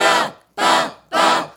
Bababa Trplt 152-E.wav